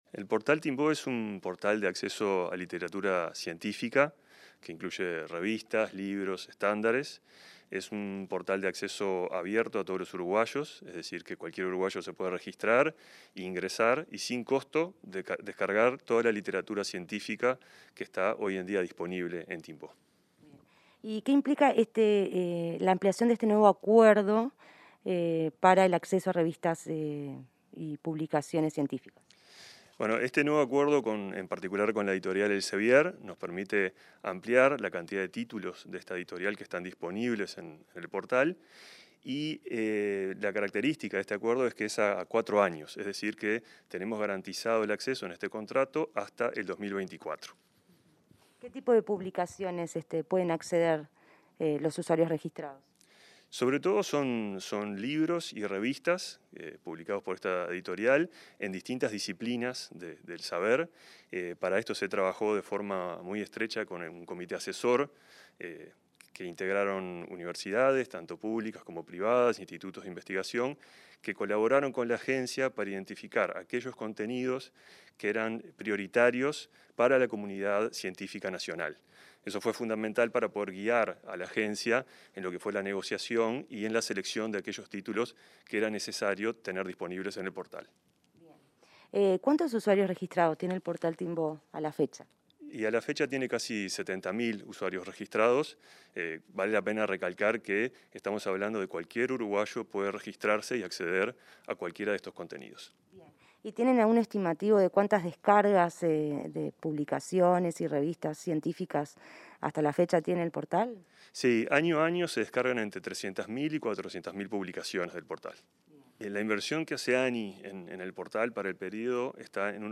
Entrevista al vicepresidente de ANII, Álvaro Pardo